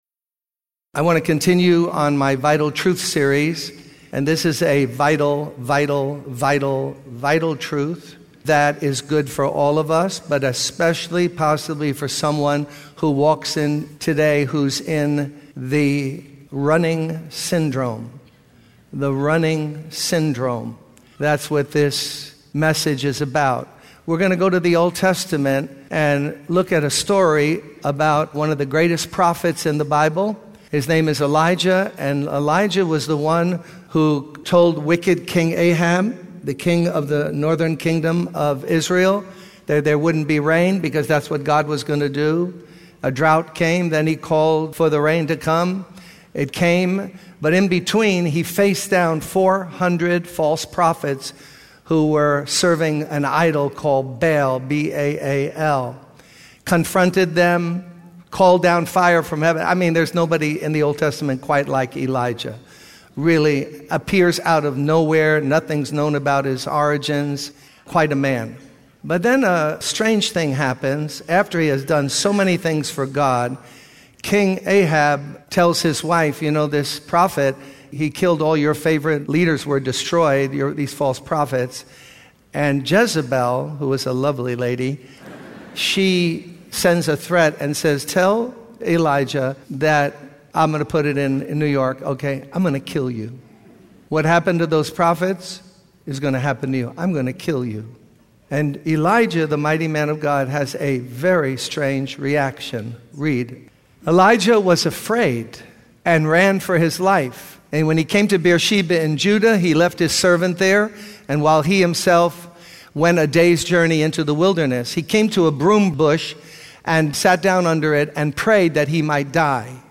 In this sermon, the speaker encourages ministers and missionaries not to quit or give up, but to come back to the Lord for strength and nourishment. He uses the story of Elijah from the Old Testament as an example of someone who faced spiritual fatigue and wanted to give up after accomplishing great things for God.